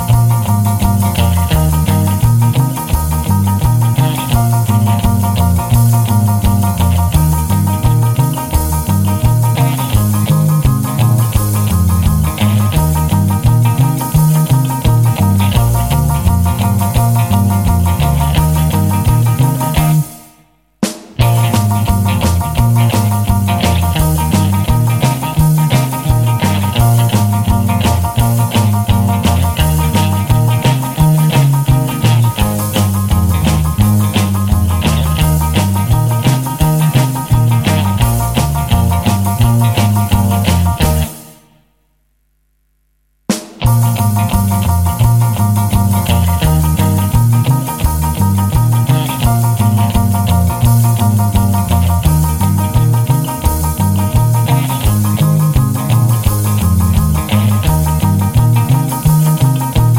No Saxophone Rock 'n' Roll 2:22 Buy £1.50